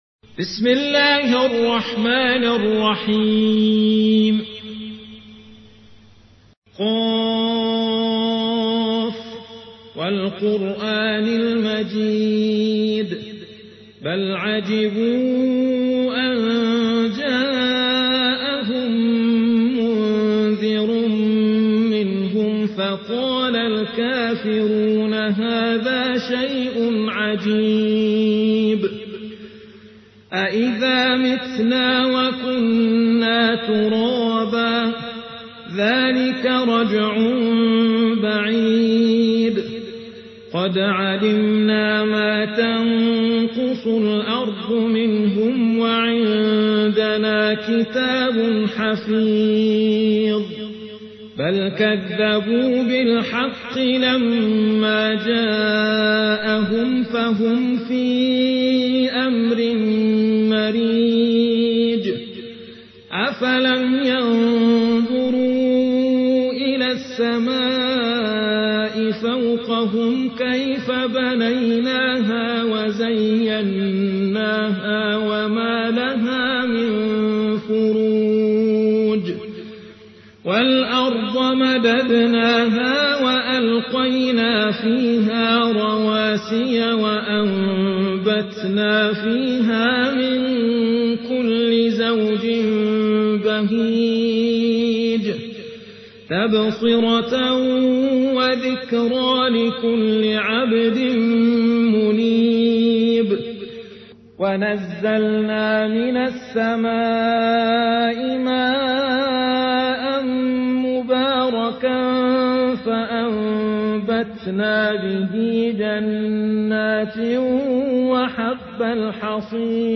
50. سورة ق / القارئ